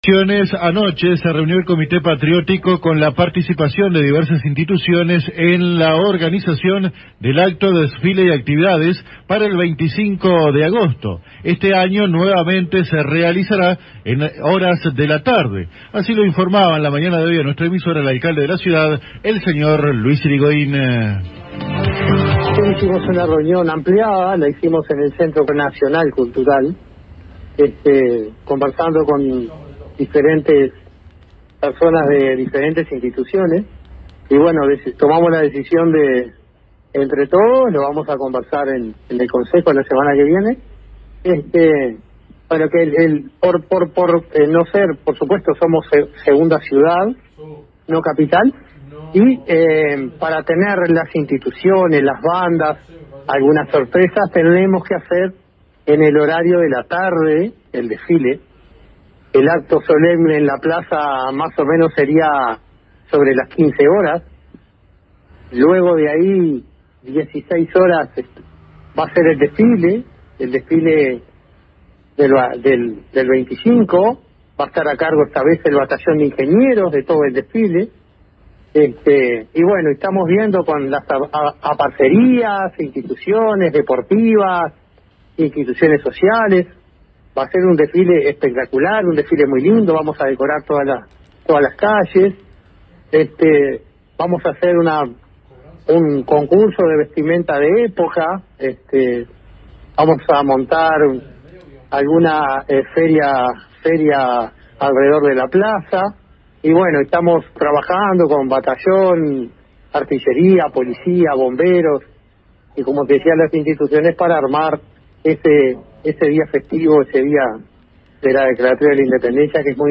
Al respecto, el Alcalde de Paso de los Toros, Luis Irigoin, conversó telefónicamente con la AM 1110 local para ofrecer detalles de la reunión, apuntando que este año se van a realizar todas las actividades en horas de la tarde, «el acto solemne en Plaza Artigas será a las 15:00 horas, y a las 16:00 comenzaría el desfile tradicional», detalló.